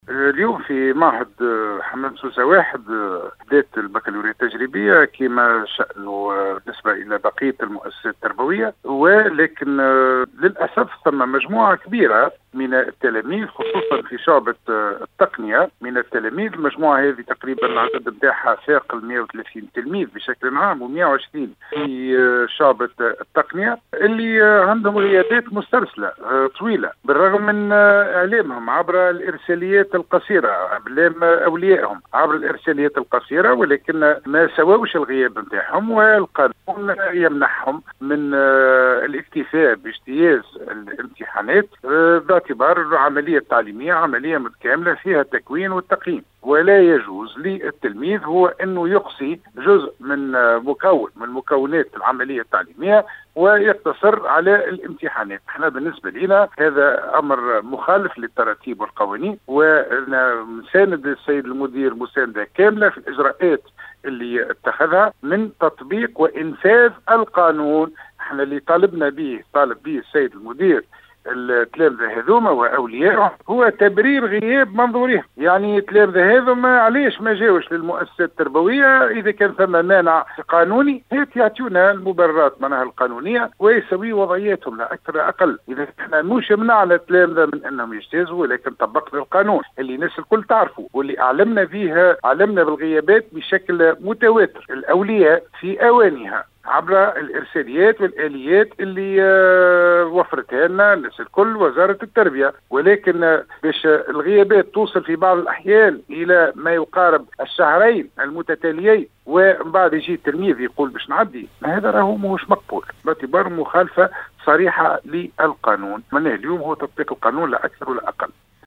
قال اليوم المندوب الجهوي للتربية بسوسة نجيب الزبيدي  في تصريح لراديو مساكن إن منع تلاميذ البكالوريا  بمعهد حمام سوسة 1 من اجتياز امتحانات الباكالوريا التجريبية  يدخل في إطار تطبيق القانوني.